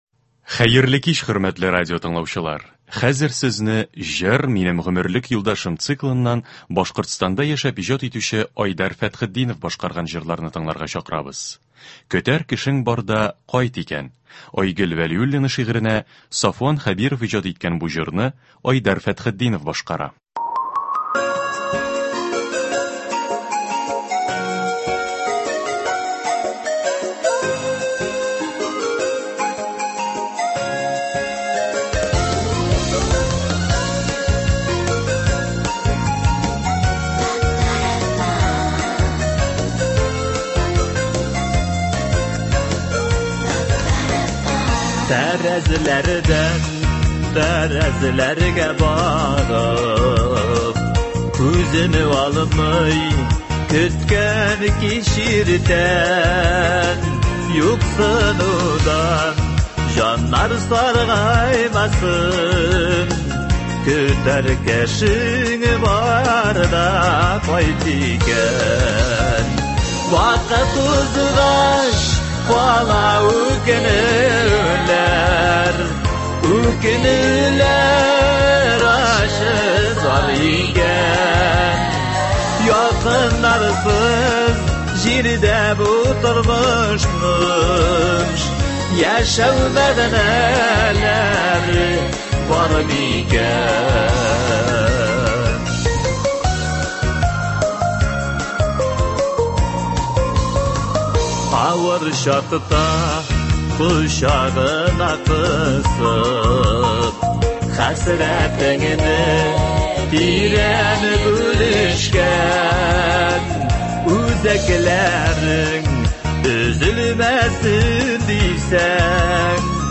Концерт (19.07.21)